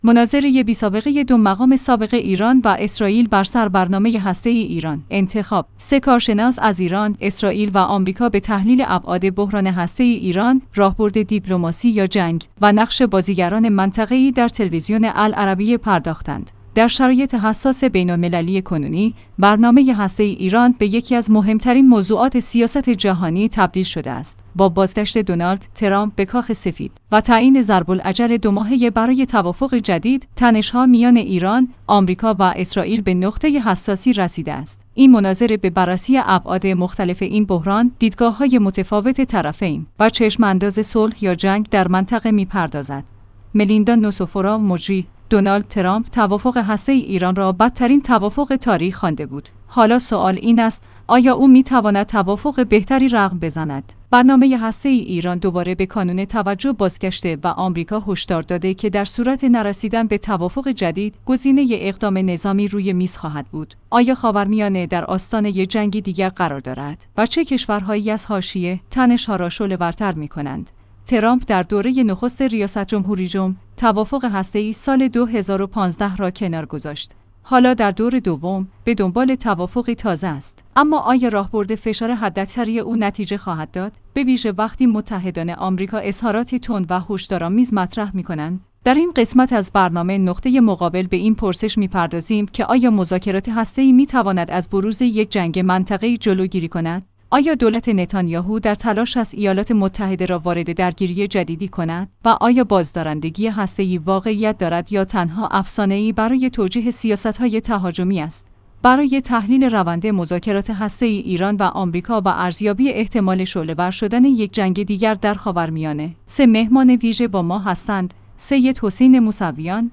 مناظره‌‌ی بی‌سابقه دو مقام سابق ایران و اسراییل بر سر برنامه هسته‌ای ایران